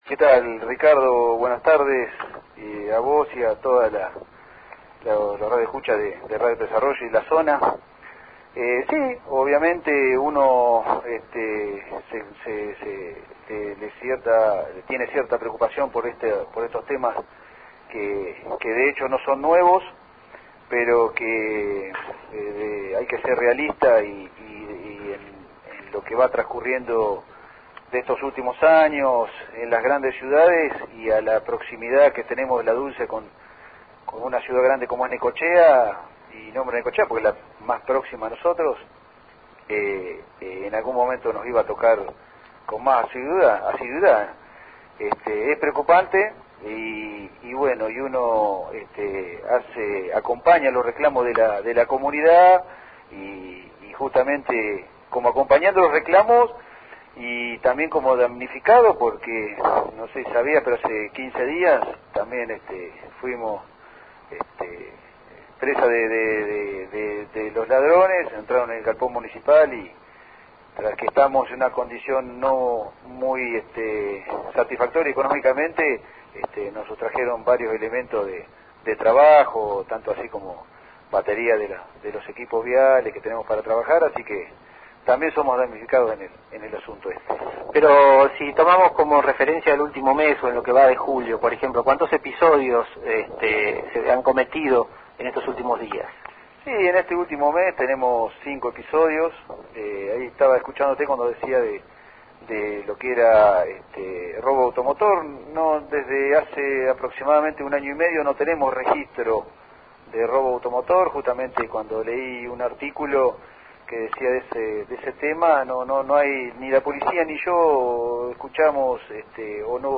Sin duda que este cuadro de situación, así planteado, es muy serio, por eso es que entrevistamos al Delegado Municipal de la vecina localidad, William Kuhn.
Escuchar audio del Delegado de La Dulce, William Kuhn, con LU 24 y JNFNet.